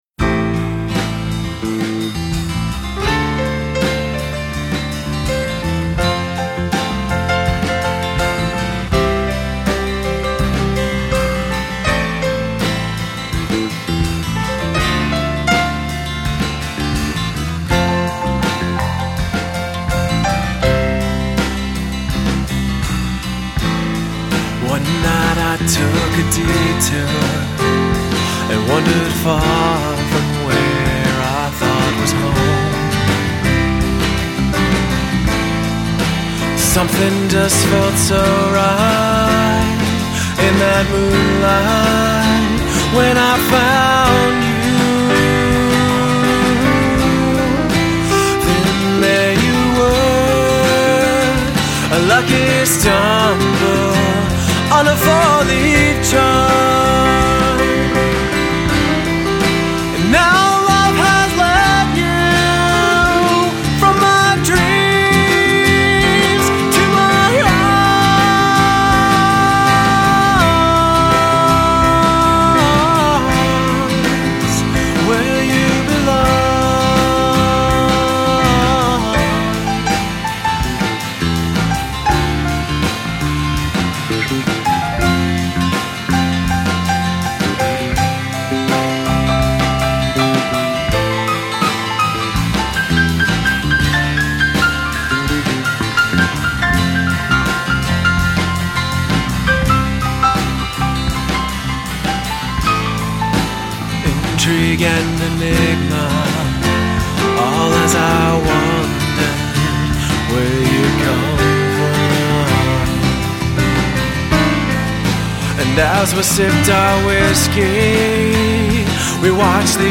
Guitar, Vocals
Drums
Piano
Bass Guitar